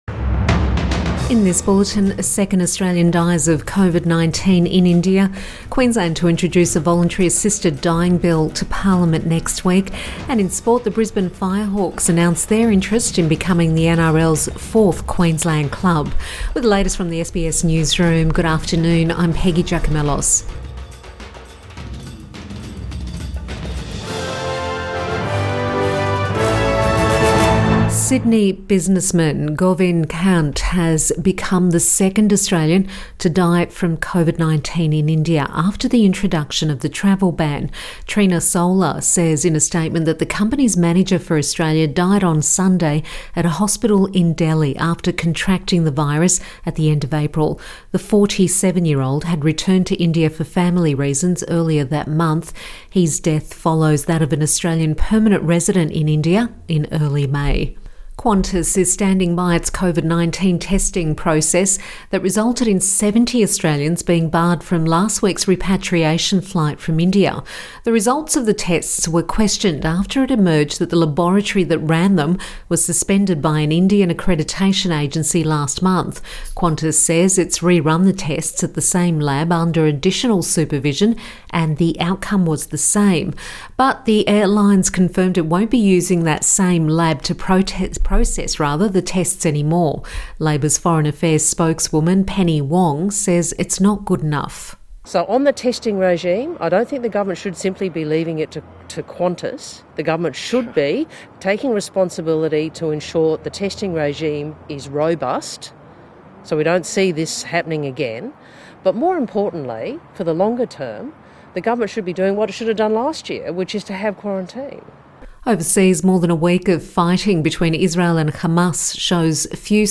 PM bulletin 18 May 2021